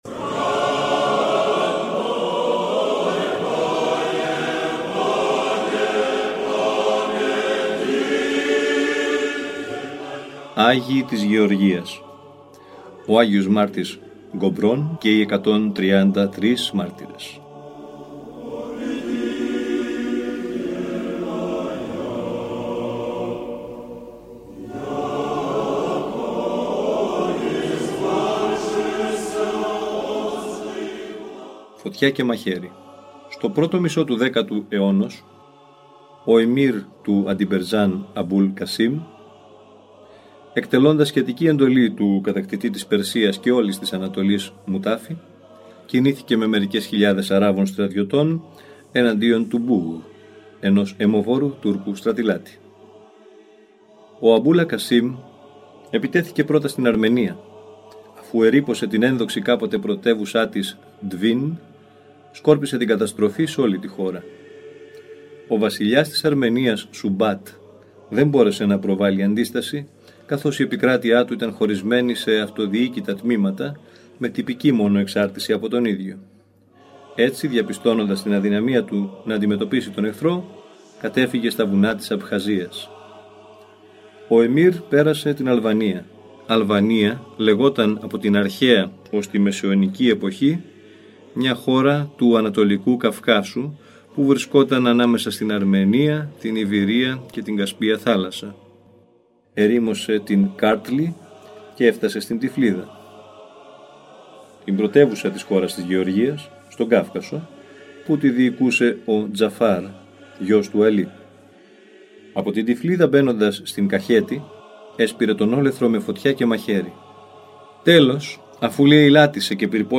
Ακούστε το επόμενο Αγιολογικό κείμενο, όπως αυτό “δημοσιεύθηκε” στο 149-ο τεύχος (Σεπτέμβριος Οκτώβριος του 2014) του ηχητικού μας περιοδικού, Ορθόδοξη Πορεία.